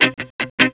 RIFFGTR 12-L.wav